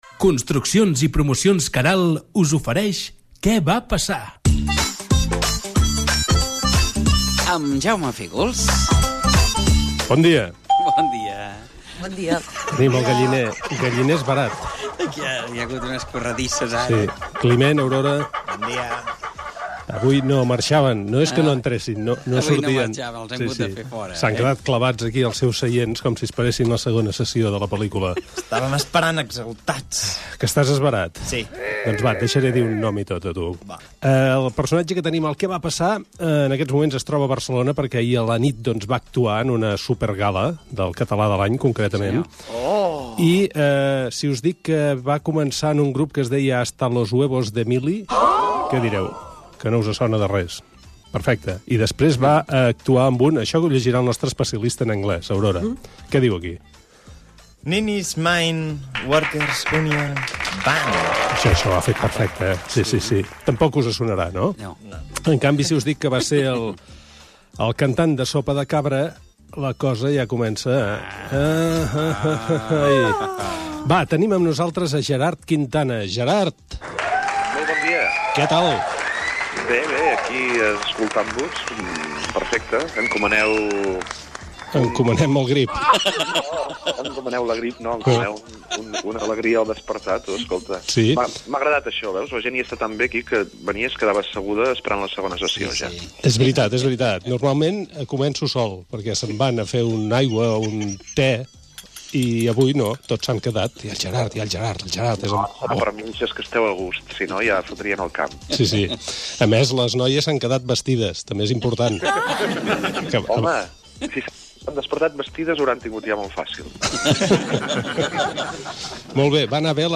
Espai "Què va passar?". Careta del programa amb publicitat i entrevista telefònica al cantant Gerard Quintana
Entreteniment